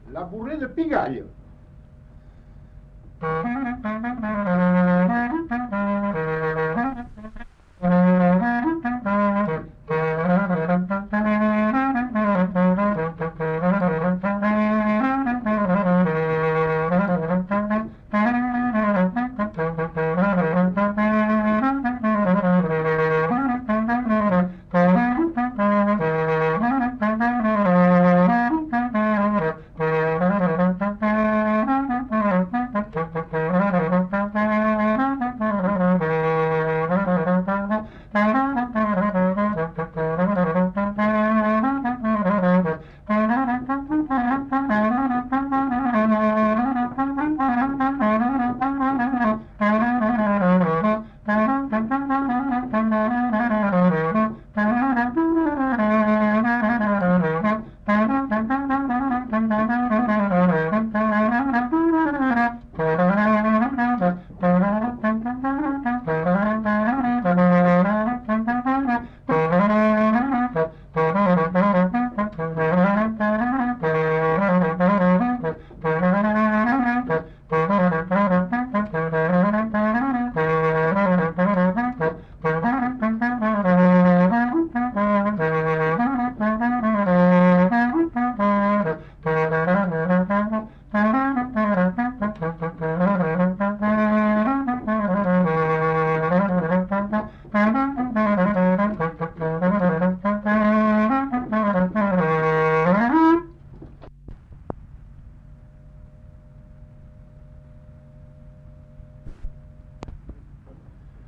Bourrée
Aire culturelle : Couserans
Département : Ariège
Genre : morceau instrumental
Instrument de musique : clarinette
Danse : bourrée